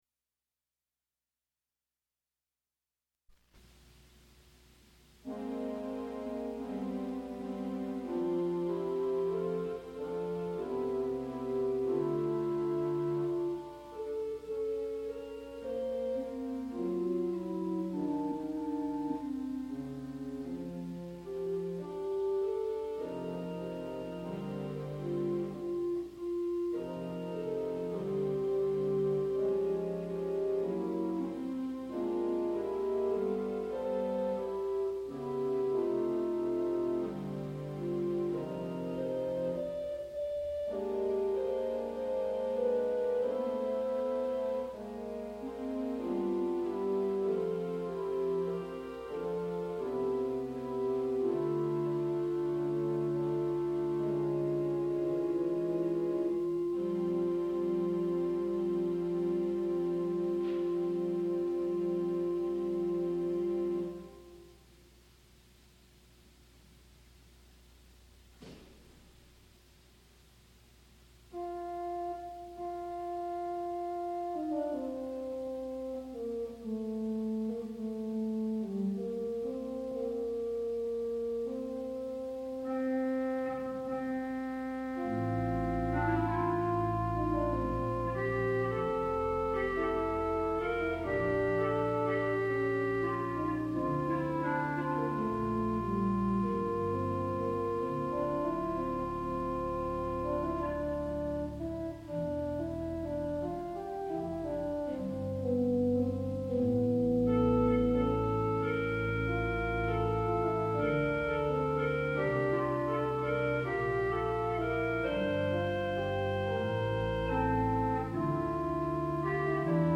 Four organ settings